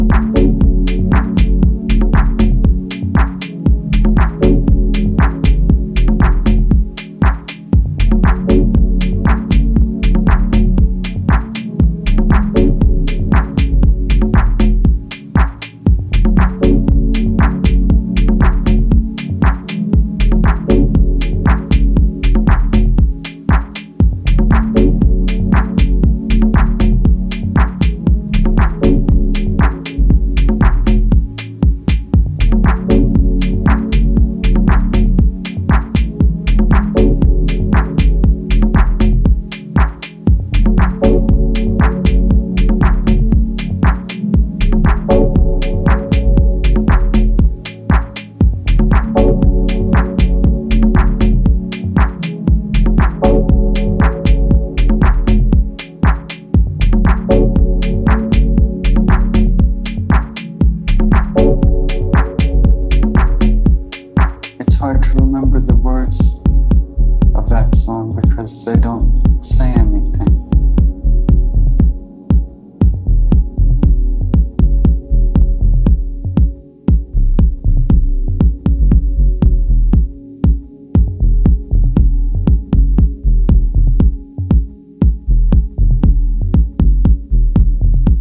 deeper than deep house